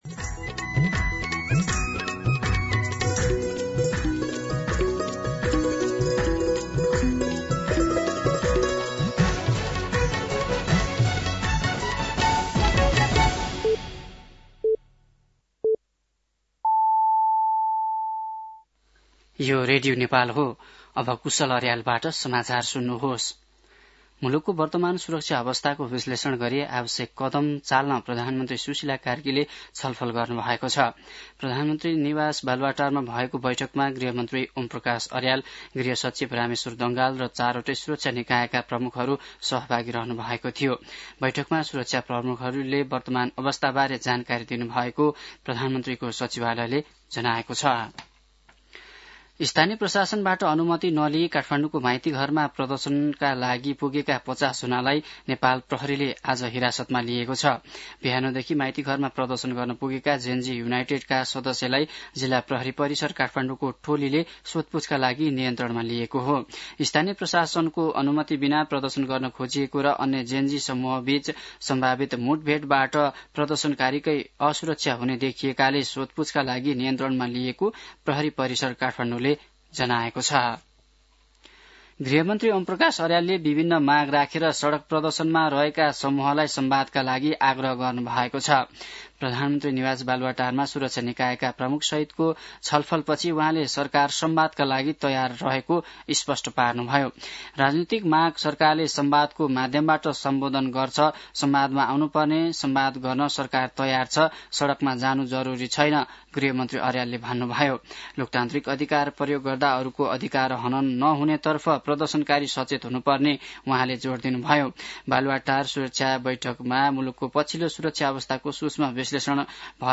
दिउँसो ४ बजेको नेपाली समाचार : ८ कार्तिक , २०८२